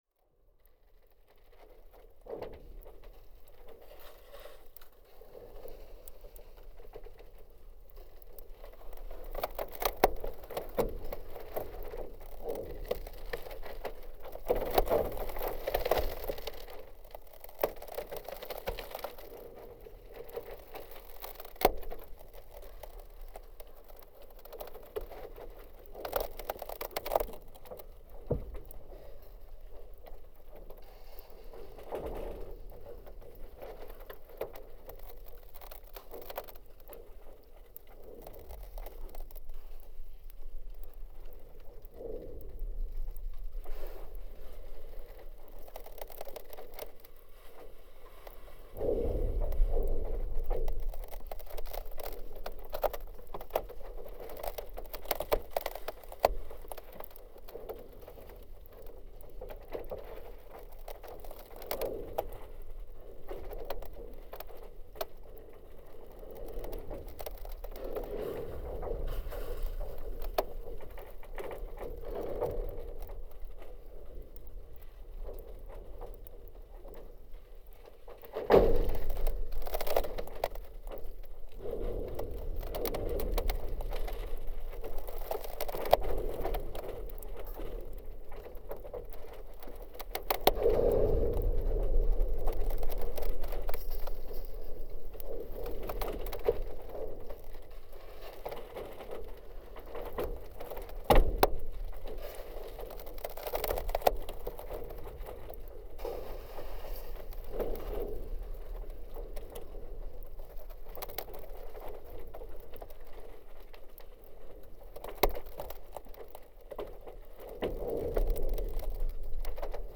Frozen lake 19th of March 2023
This gave me the opportunity to record under ice, which I hadn’t done before. I invested in a proper ice drill and managed to make three trips to Skorradalsvatn lake to record under and over the ice.
It was surprising that the sounds from frozen lakes are not produced by the frost alone and the variable expansion of the ice.
Especially under the ice, where there is clearly significantly more noise than on the surface. On the surface, you hear mainly „drone squeals“ and occasional clicks and breaks, which are also interesting to record. The following recording was recorded approx. in 8 meters depth, not far from the place where the lake is deepest, or about 60 meters.